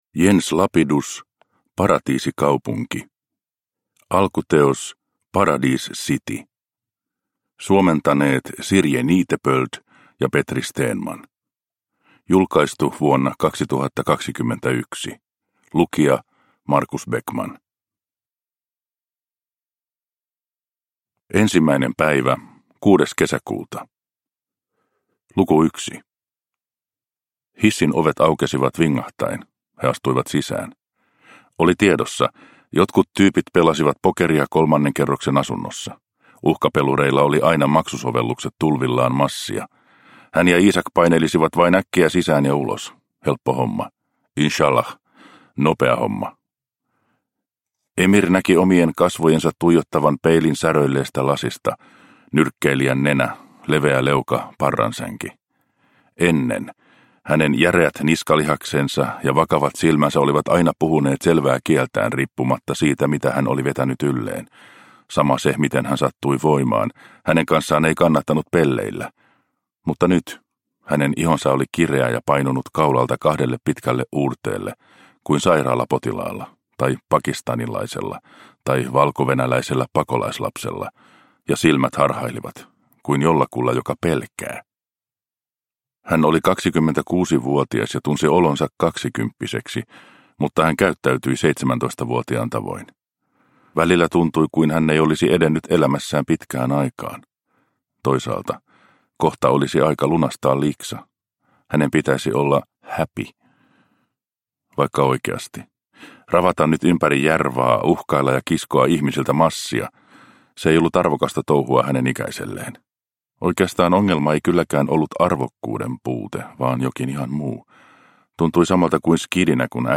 Paratiisikaupunki – Ljudbok – Laddas ner